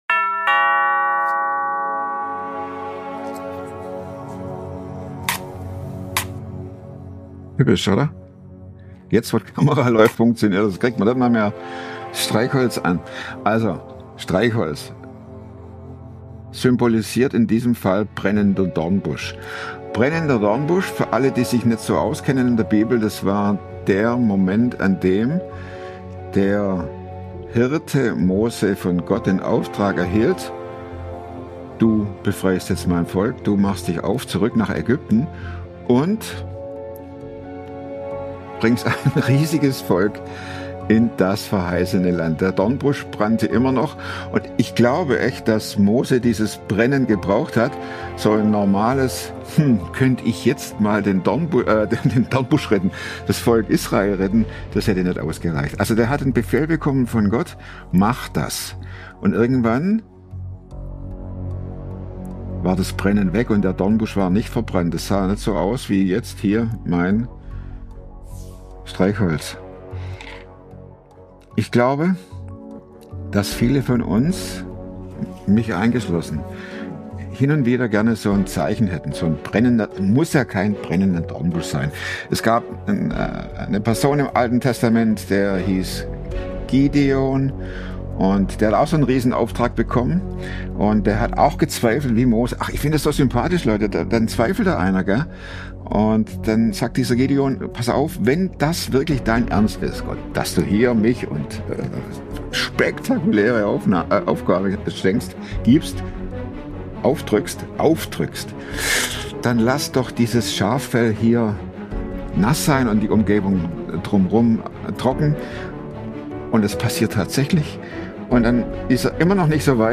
Ein ehrliches Gespräch über Burnout, Angst, Loslassen und darüber, warum Heilung Zeit, Bewegung und Wahrheit braucht.